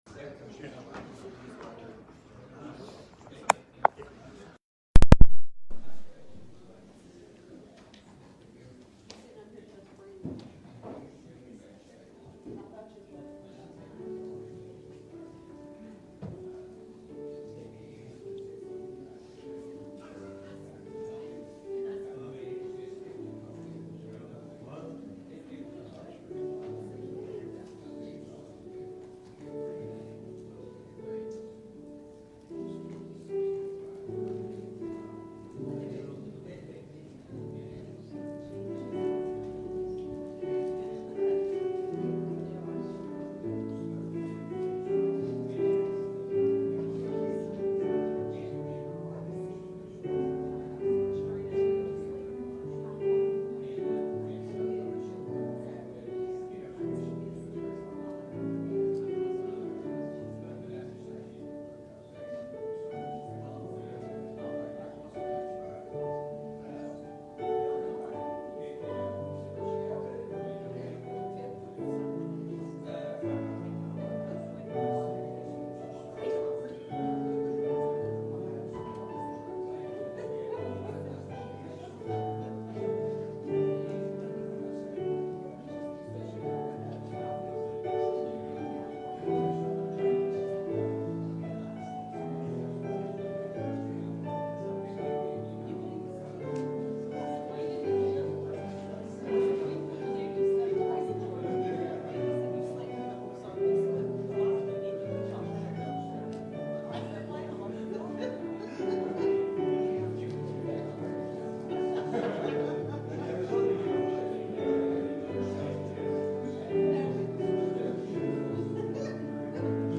Dealing with Sin in the Church | SermonAudio Broadcaster is Live View the Live Stream Share this sermon Disabled by adblocker Copy URL Copied!